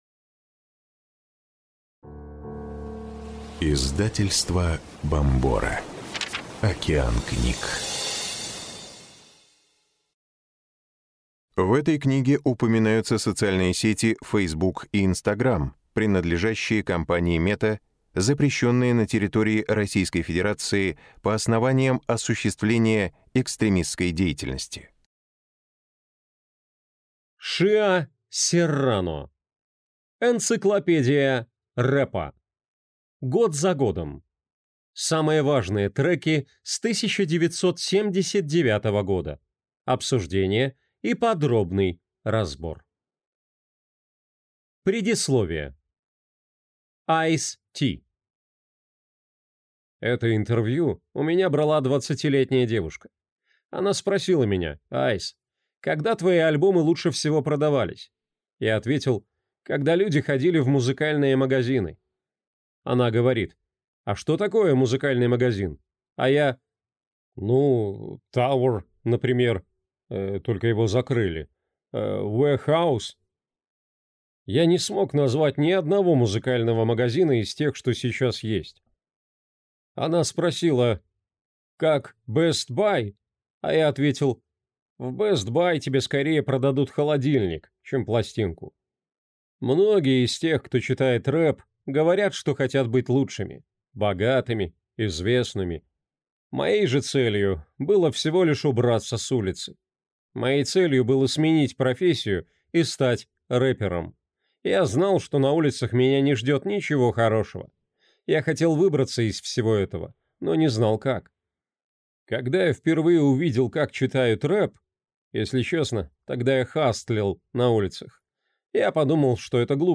Аудиокнига «Энциклопедия рэпа. Год за годом. Самые важные треки c 1979 года: обсуждение и подробный разбор». Автор - Ice-T.